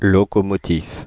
Ääntäminen
Ääntäminen Paris: IPA: [lɔ.kɔ.mɔ.tif] Haettu sana löytyi näillä lähdekielillä: ranska Käännöksiä ei löytynyt valitulle kohdekielelle.